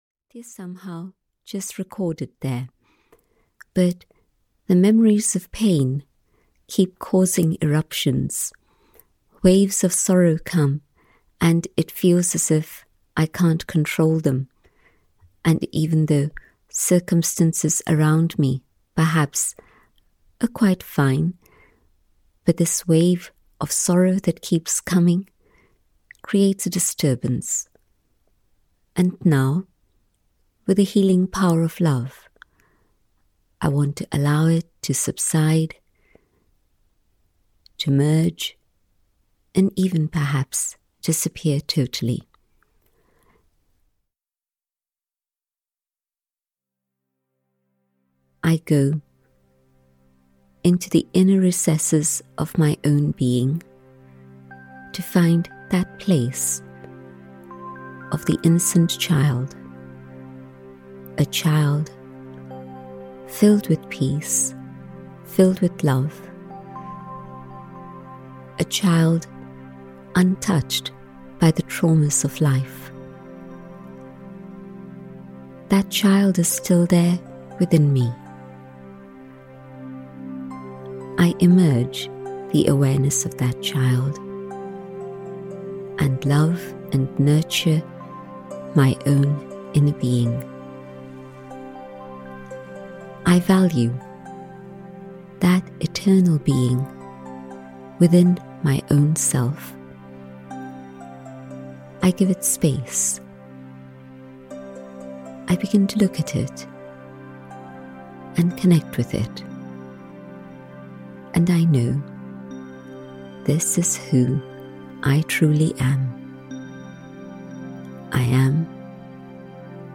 Ukázka z knihy
These short commentaries and the beautiful, calming music accompanying them will help you come closer to understanding your inner self.